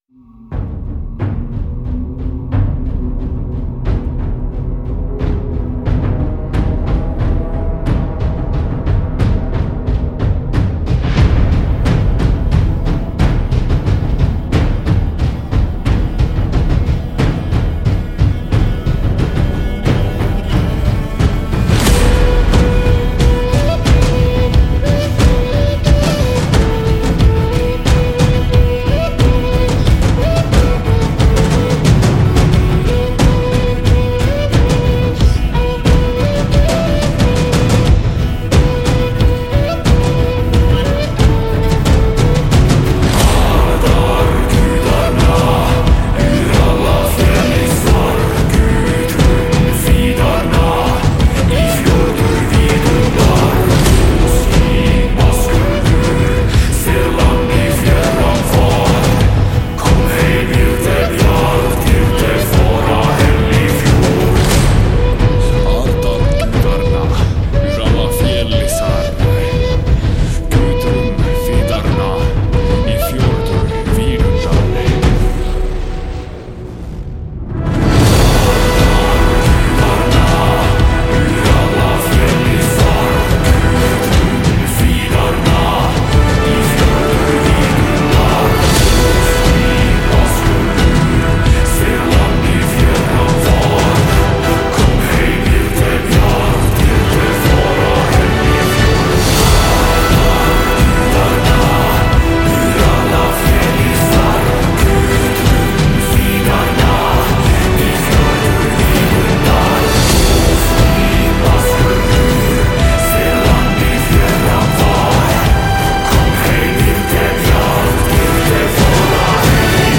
影视史诗鼓 Audio Imperia Cerberus KONTAKT-音频fun
它还有敲击、持续滚动和渐强滚动等音色，以及循环和声音设计等功能。
Cerberus与我们的旗舰管弦乐库Jaeger在同一录音舞台上录制，提供了一种简单的方法，通过全新的堆叠系统构建超凡脱俗的鼓合奏。
为了使库更加灵活，我们录制了一个带有钹的个人架子鼓以及一个雷鸣般的Gran Cassa合奏。
• 紧凑而有力的鼓/打击乐三重奏。
• 命中，持续滚动和渐强滚动。